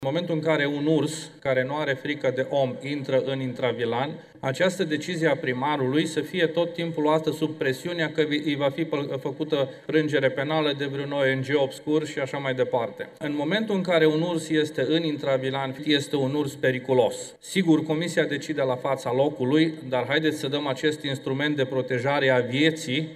Gheorghe Nacov, deputat din partea minorităților naționale: „În momentul în care un urs este în intravilan, este un urs periculos”